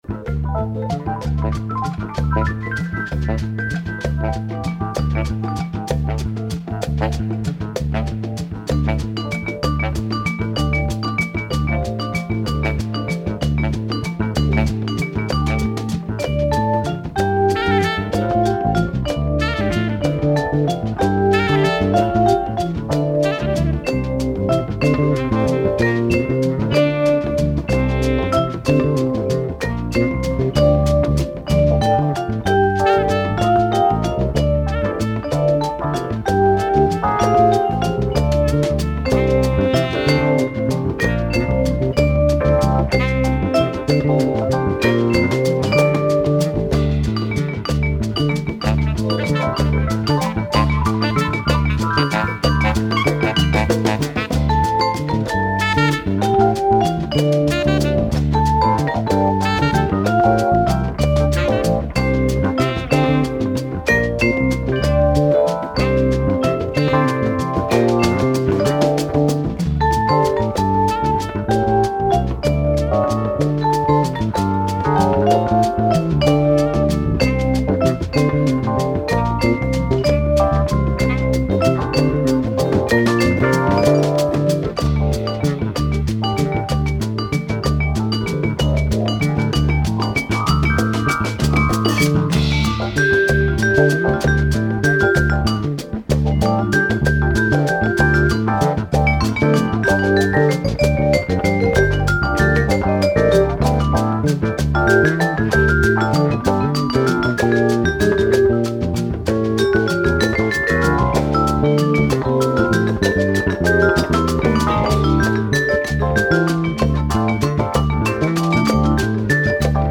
marimba